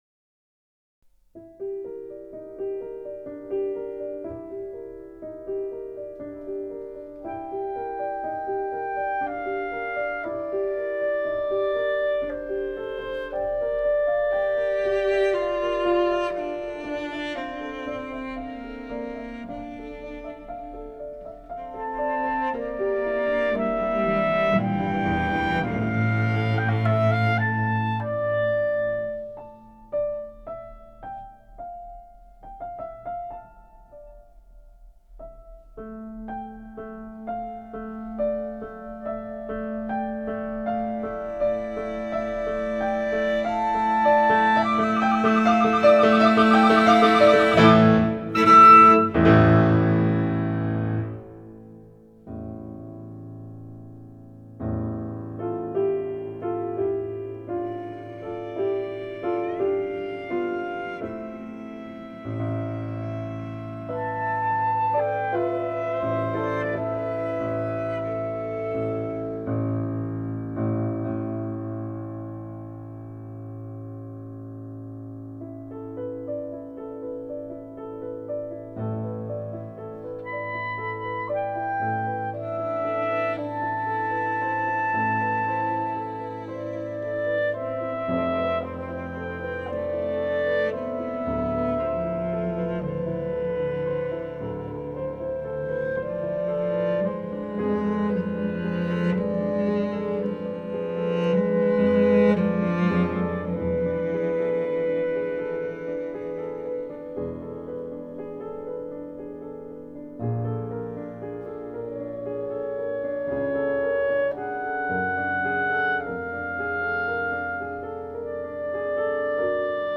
I soon added a cello part to it, and rearranged it for clarinet trio, for my brother’s wedding.
Clarinet, cello & piano.